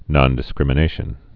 (nŏndĭ-skrĭmə-nāshən)